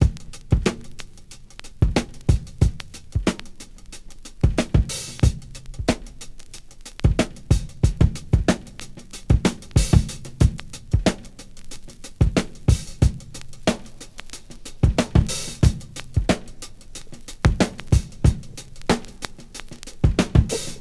• 92 Bpm Breakbeat Sample C# Key.wav
Free drum loop sample - kick tuned to the C# note. Loudest frequency: 772Hz
92-bpm-breakbeat-sample-c-sharp-key-3ee.wav